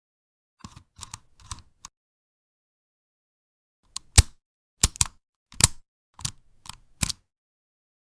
Unlock Pure Satisfaction 🔐 Slow turn, deep click. The old lock whispers with every movement.